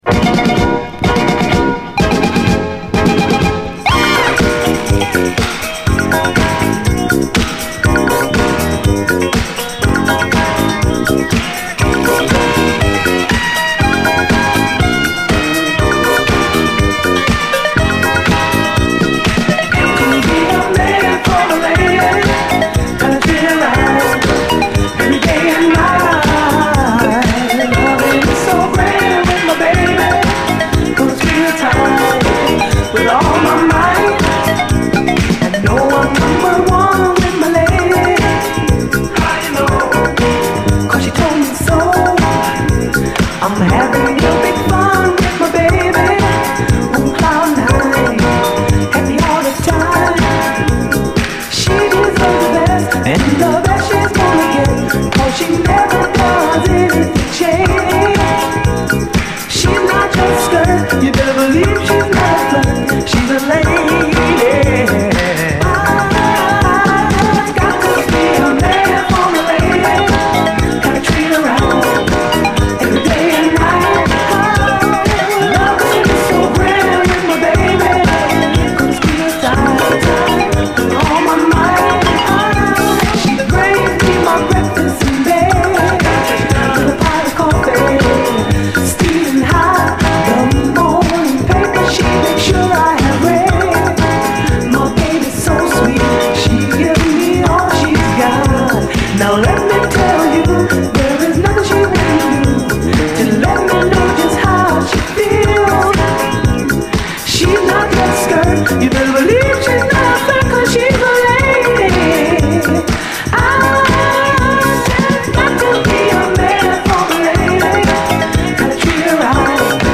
SOUL, 70's～ SOUL, DISCO